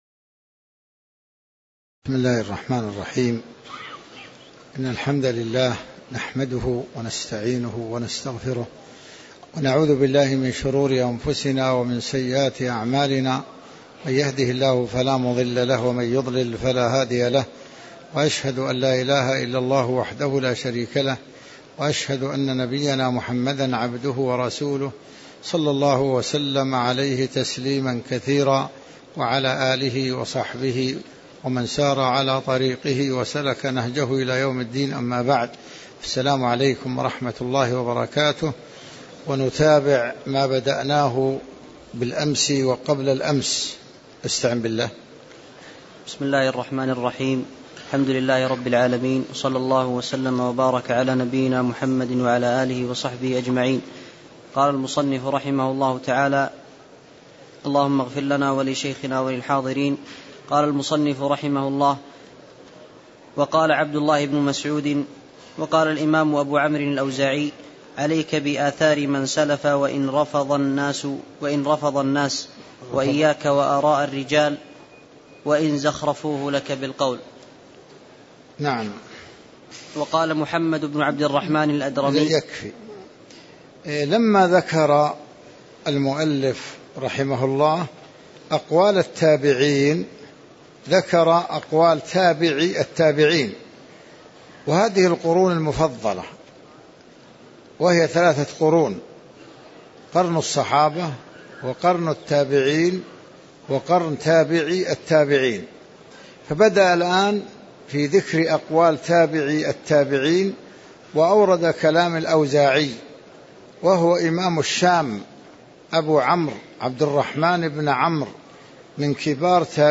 تاريخ النشر ٥ جمادى الآخرة ١٤٣٧ هـ المكان: المسجد النبوي الشيخ